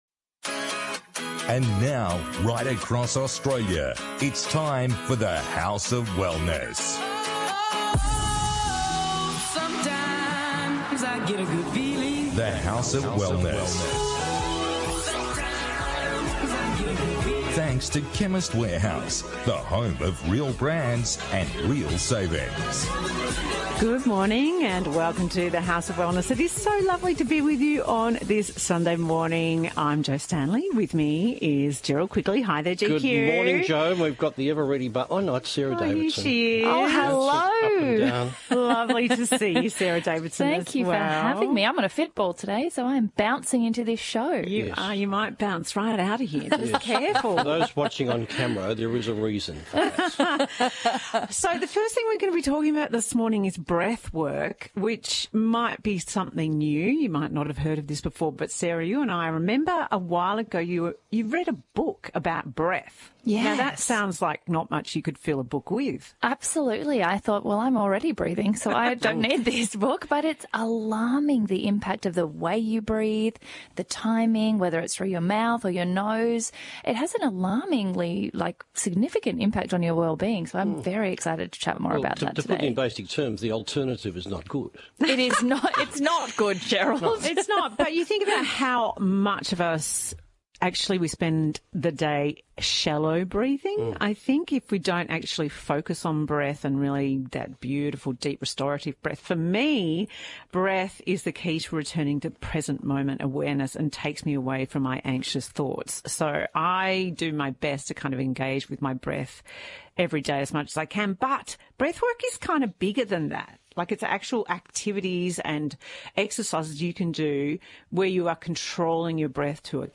The House of Wellness Radio - Full Show 28th May 2023